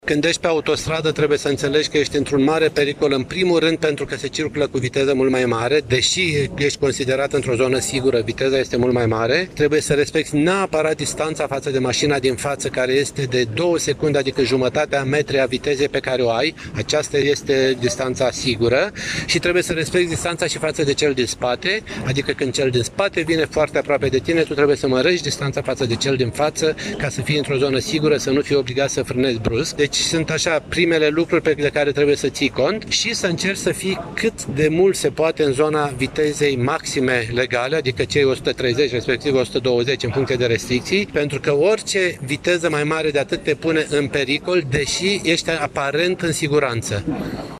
Conferința organizată pe această temă, a educației pentru siguranță în trafic, a avut loc în Sala Mare a Primăriei Iași.